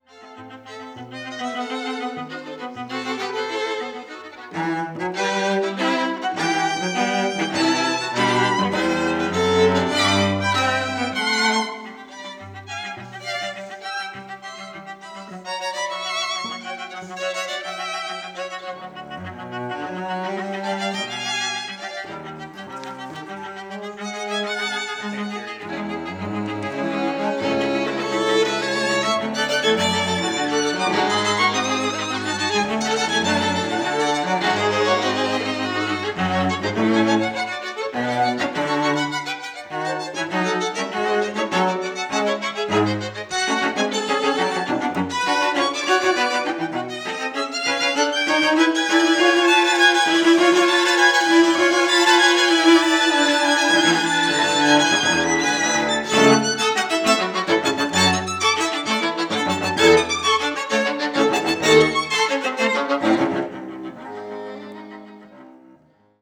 Tetrahedral Ambisonic Microphone
Recorded February 4, 2010, Jessen Auditorium, University of Texas at Austin. Quartet practicing for recording session.
Credits: Aeolus String Quartet.